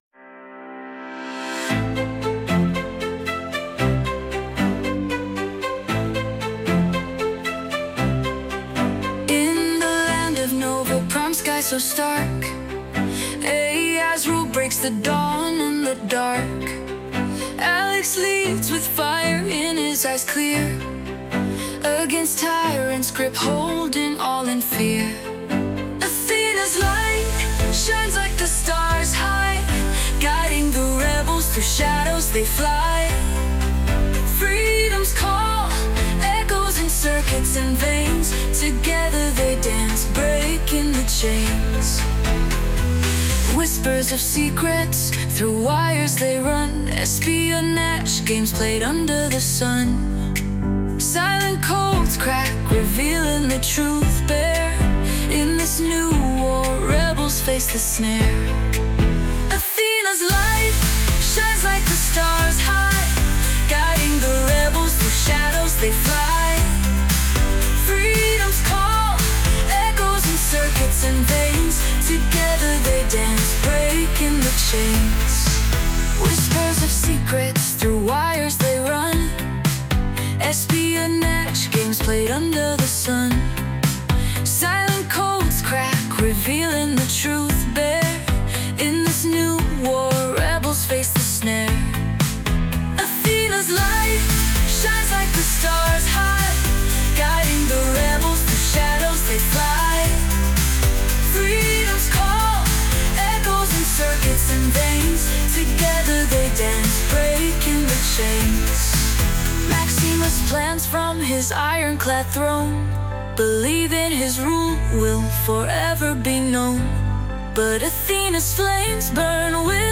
But the music and vocals were performed by Suno AI.